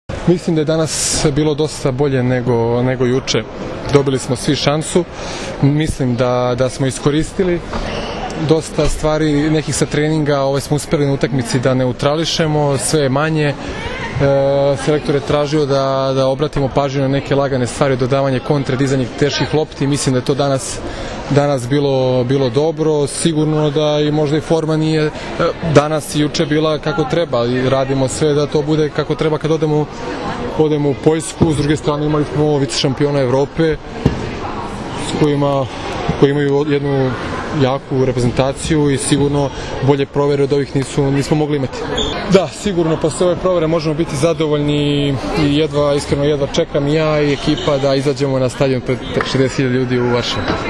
IZJAVA MILANA KATIĆA